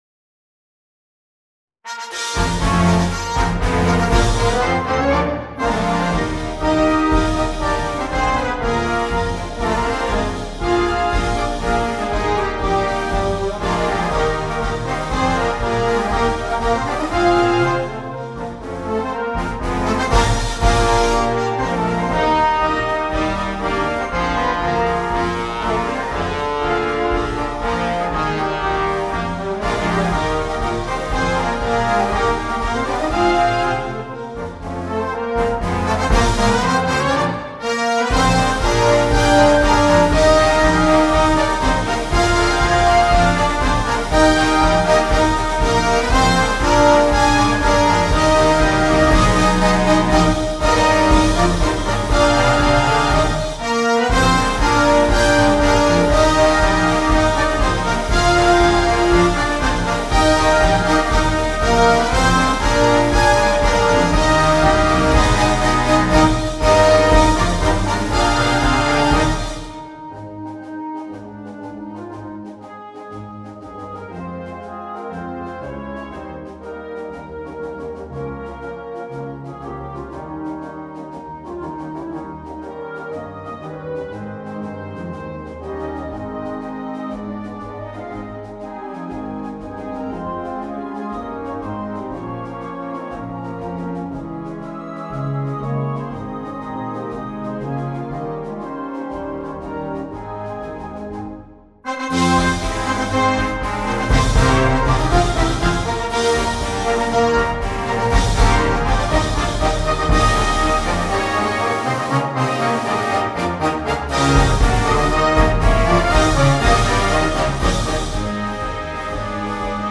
Concert March